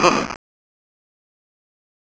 scavengers_die.aif